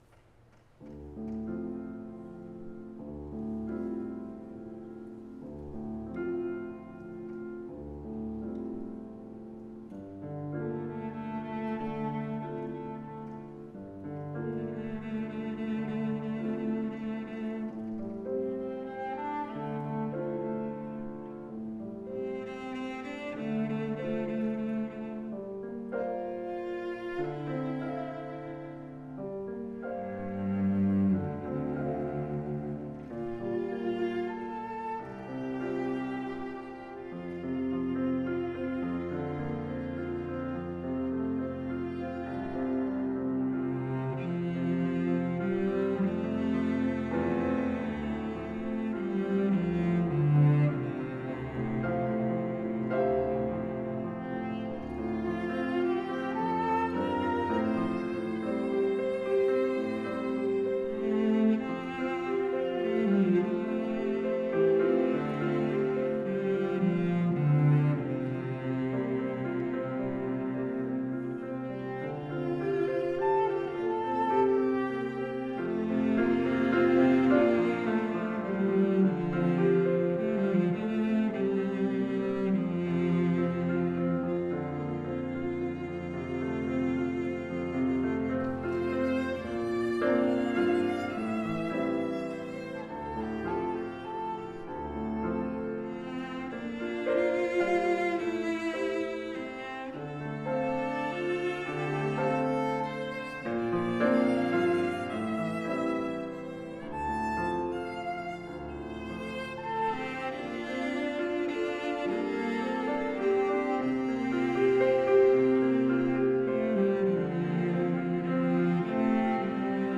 for Viola, Cello, and Piano (2021)
The arrangement ends triumphantly, marked with strength.